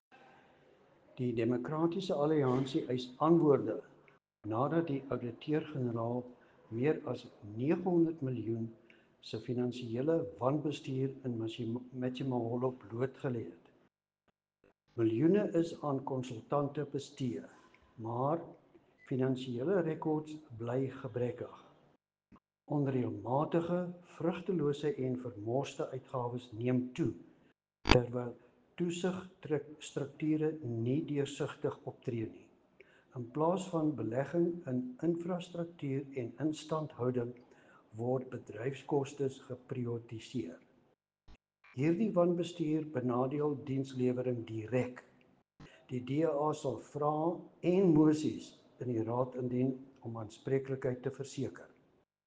Afrikaans soundbite by Cllr Louis Van Heerden.